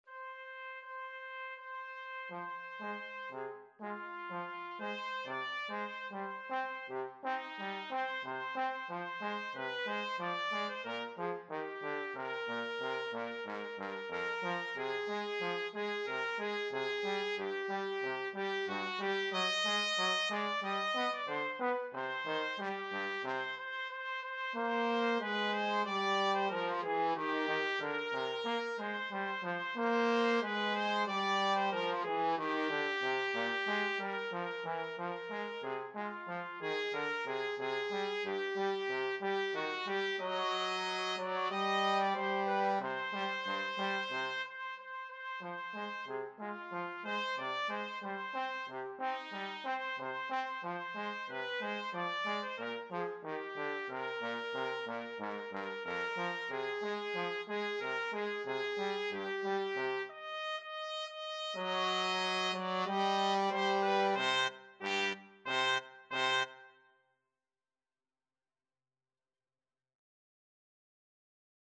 Free Sheet music for Trumpet-Trombone Duet
Eb major (Sounding Pitch) F major (Trumpet in Bb) (View more Eb major Music for Trumpet-Trombone Duet )
4/4 (View more 4/4 Music)
Steadily (first time) =c.60
Traditional (View more Traditional Trumpet-Trombone Duet Music)